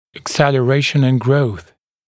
[əkˌselə’reɪʃn ɪn grəuθ][экˌсэлэ’рэйшн ин гроус]ускорение роста